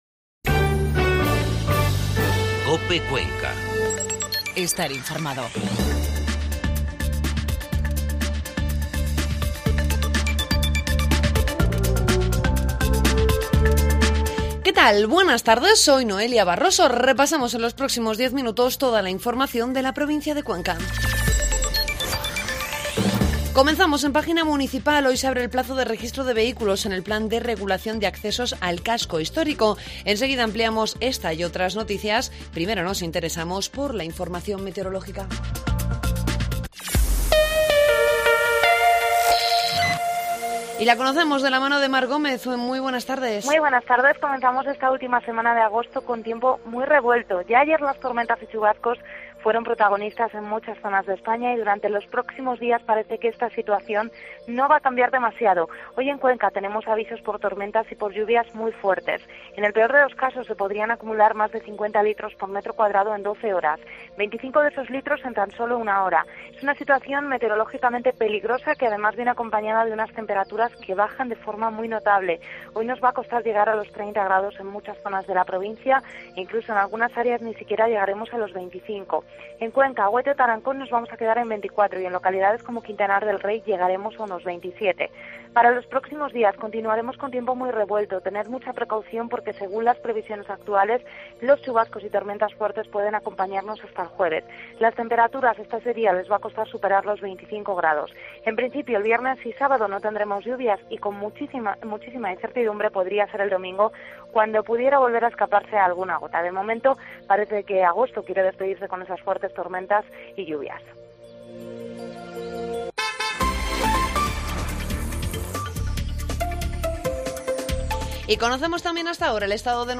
AUDIO: Informativo COPE Cuenca